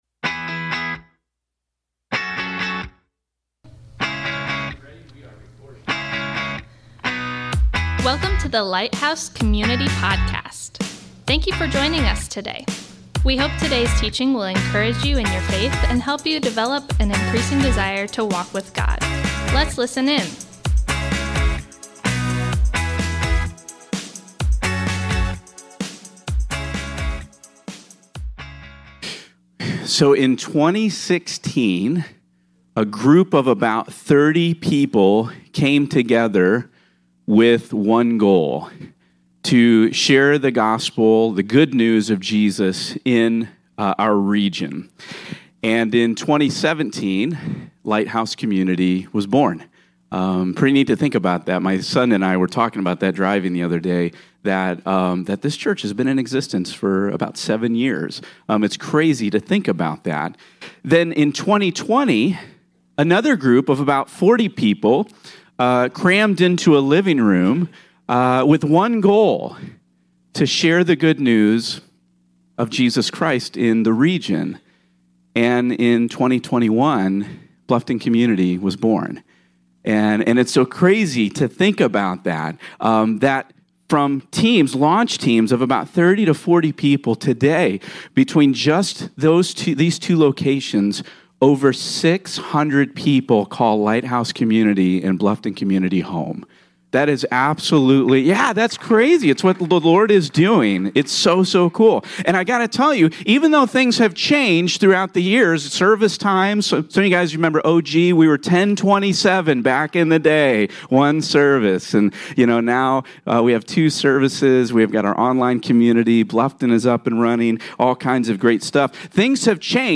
Today's teaching is "Saying Yes to Jesus". We are looking at why Lighthouse Community says "We say Yes to Jesus".